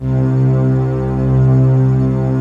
DM PAD2-95.wav